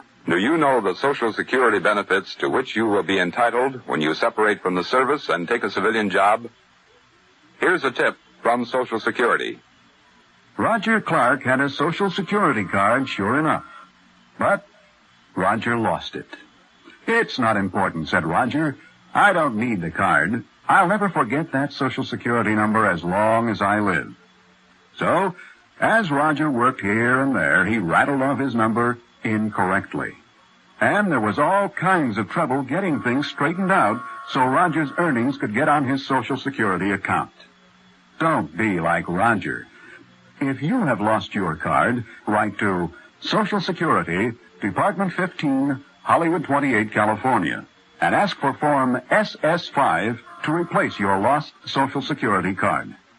public service announcements
AFRS A tip from Social Security, 1957, MP3, 446k